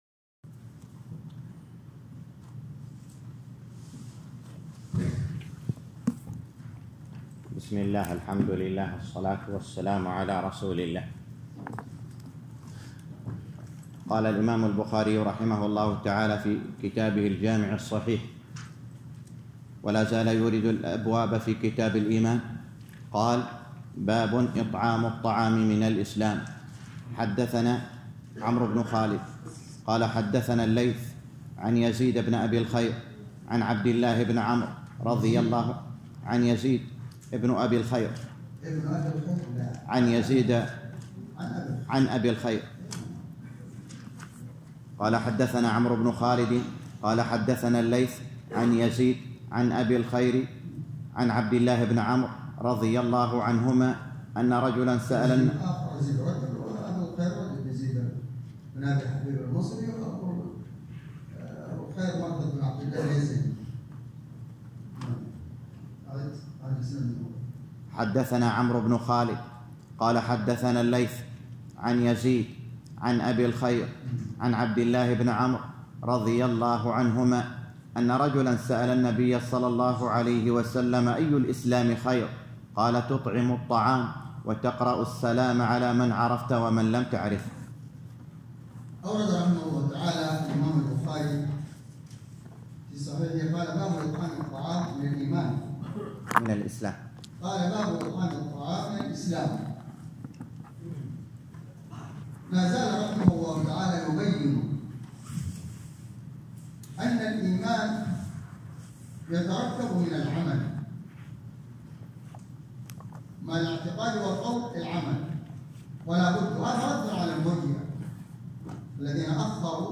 الدرس الرابع - شرح صحيح البخاري كتاب الإيمان _ 4